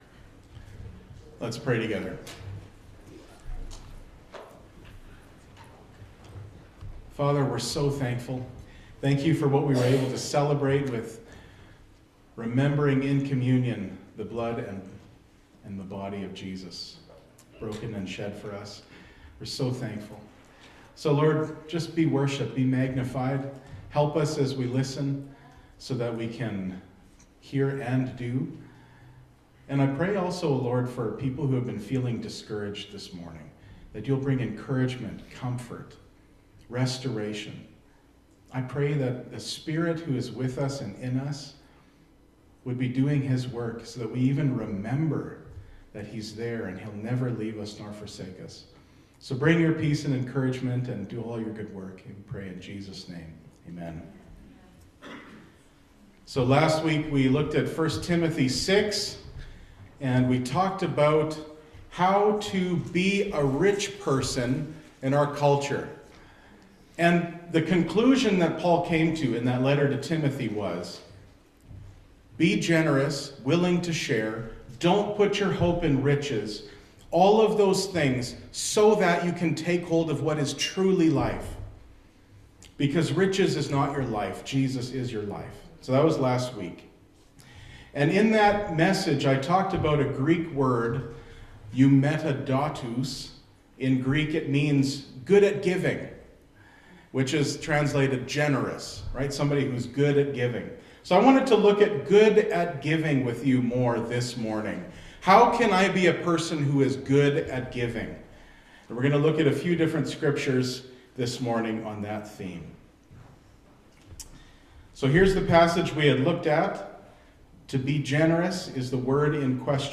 2025 Getting good at giving Pastor